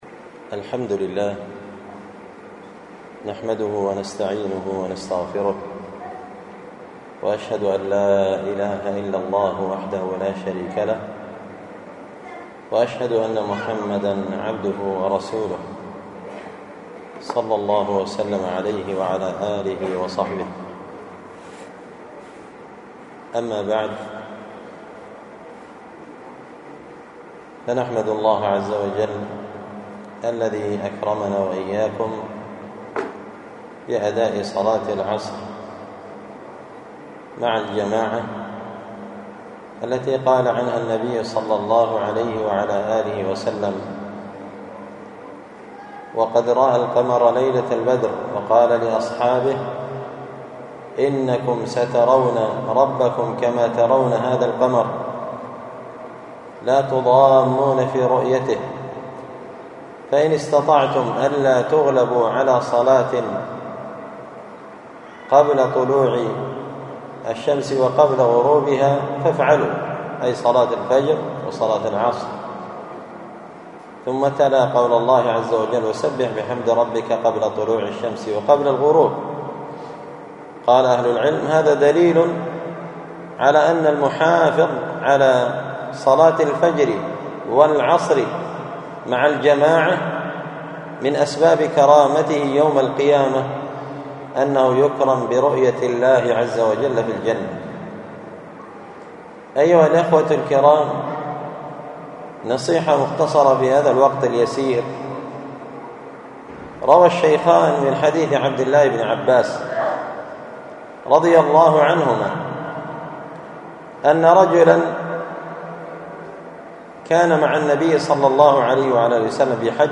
الجمعة 20 شعبان 1445 هــــ | الخطب والمحاضرات والكلمات | شارك بتعليقك | 50 المشاهدات